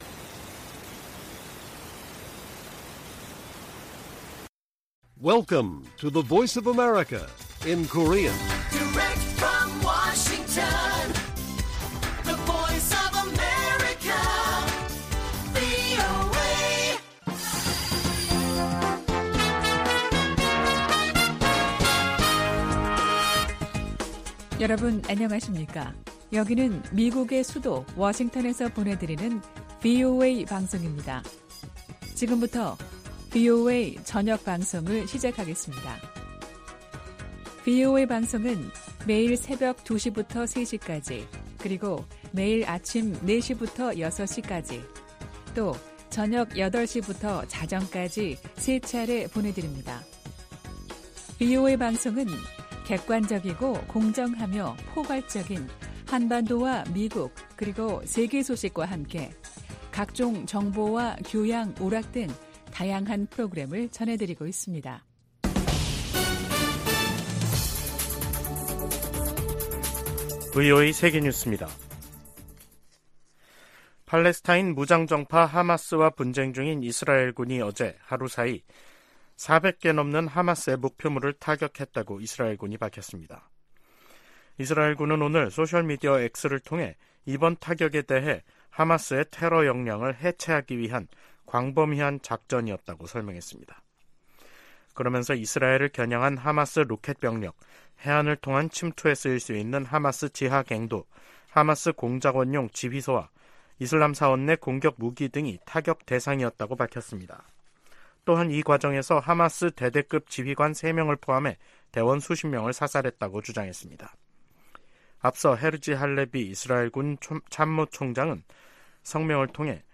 VOA 한국어 간판 뉴스 프로그램 '뉴스 투데이', 2023년 10월 23일 1부 방송입니다. 북한 주민 4명이 소형 목선을 타고 동해 북방한계선(NLL)을 통과해 한국으로 넘어 왔습니다. 미국 정부가 북한과 러시아의 무기 거래 현장으로 지목한 북한 라진항에 또다시 대형 선박이 정박한 모습이 포착됐습니다.